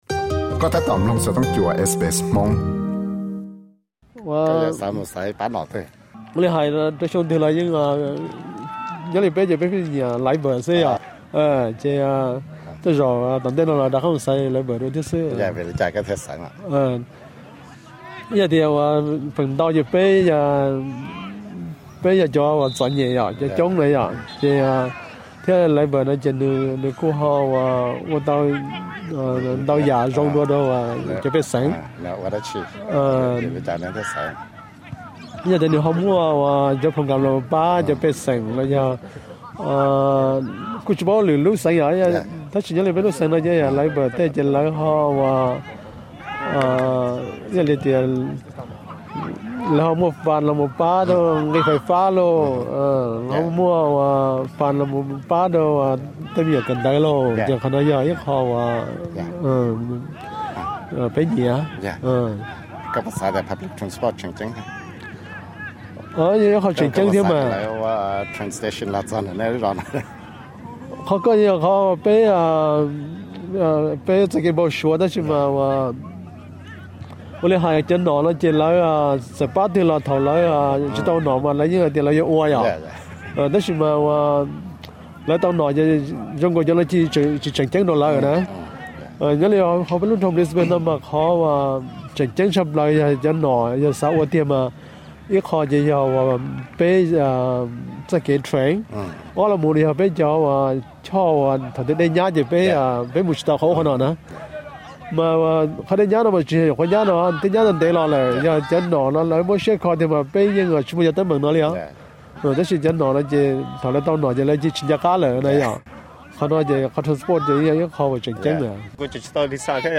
Koj puas tau npaj siab tias yuav xaiv pab nom twg rau cov kev xaiv tsa tsoom fwv teb chaws Australia xyoo 2025 no? Ntxiv no yog 2 tug tub koom siab Hmoob ntawm Brisbane tawm tswv yim tias vim li cas nkawv thiaj yuav xaiv ib pab nom twg?